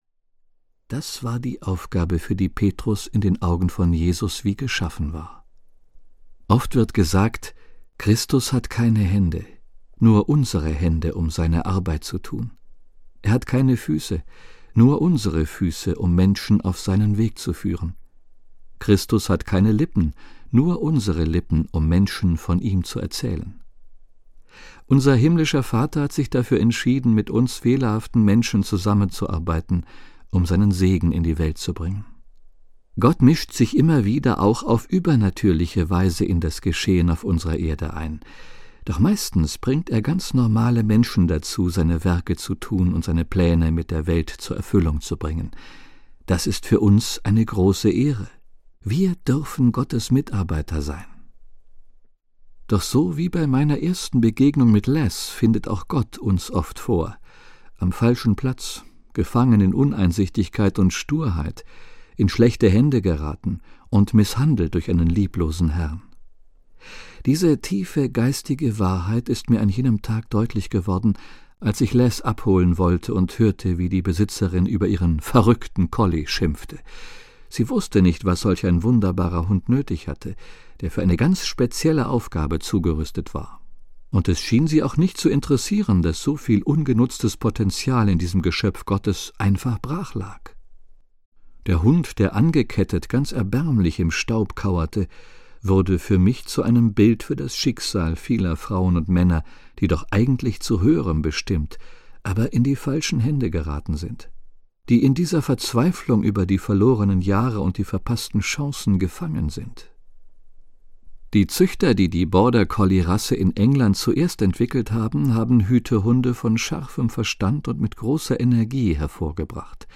Was mein Hirtenhund mich lehrte - Phillip Keller - Hörbuch